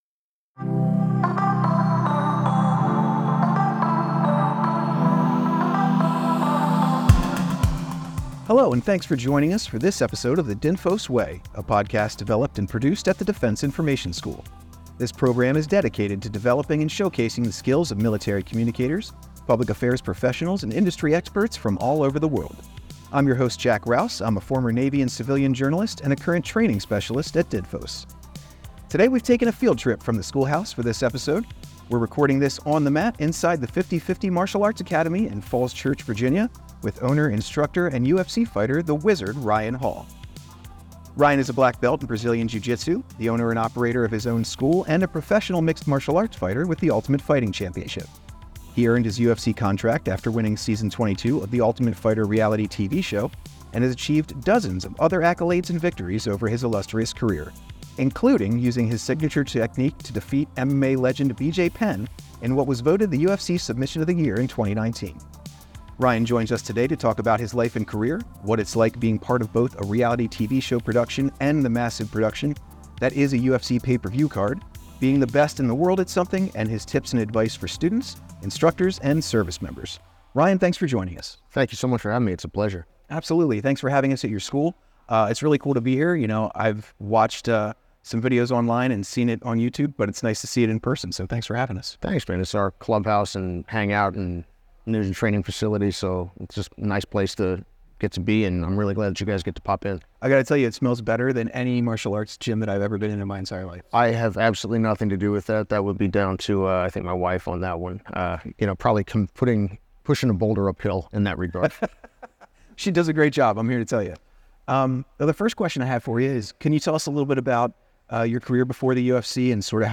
Don’t miss this engaging conversation on discipline, excellence, and the power of effective storytelling!